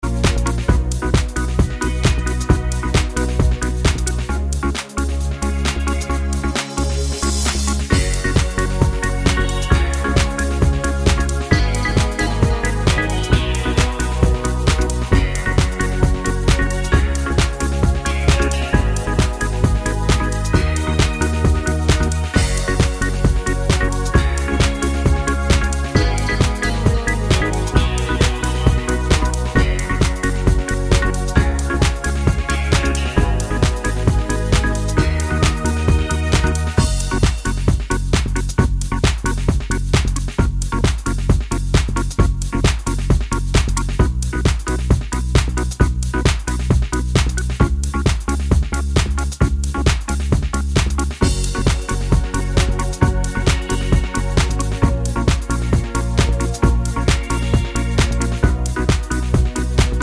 No Backing Vocals or Samples Pop (1990s) 4:09 Buy £1.50